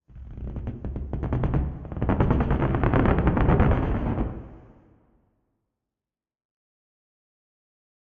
Minecraft Version Minecraft Version latest Latest Release | Latest Snapshot latest / assets / minecraft / sounds / ambient / nether / warped_forest / creak1.ogg Compare With Compare With Latest Release | Latest Snapshot
creak1.ogg